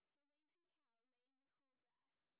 sp17_street_snr30.wav